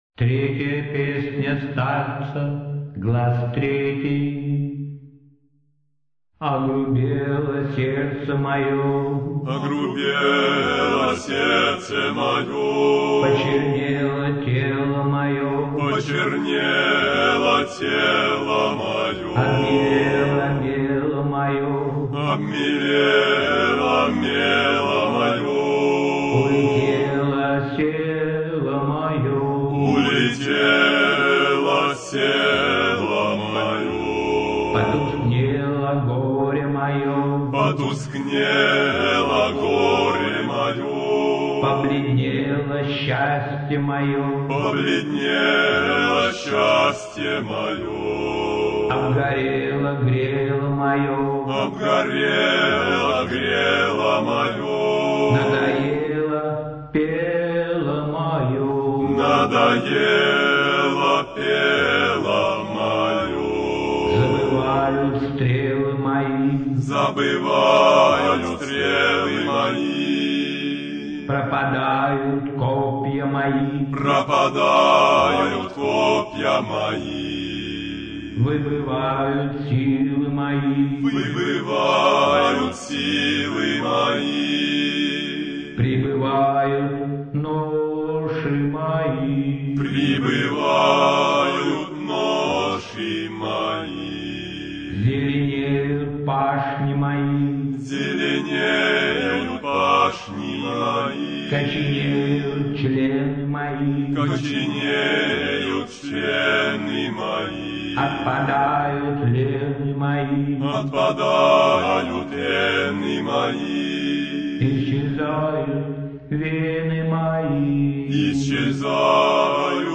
Всю композицию (mono, 40 kbps, 1103 kb) вы можете скачать